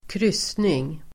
Ladda ner uttalet
Uttal: [²kr'ys:ning]